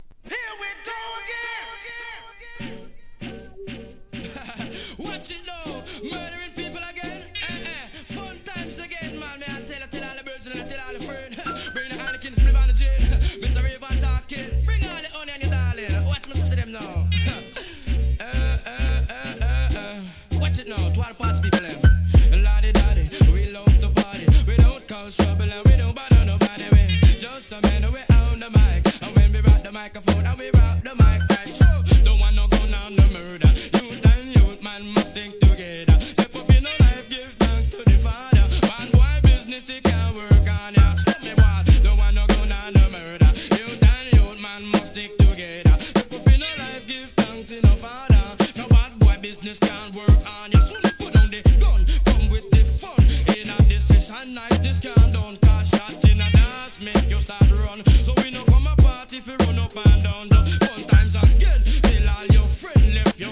HIP HOP/R&B
MEGA MIXオケでのラガHIP HOP CLASSIC!!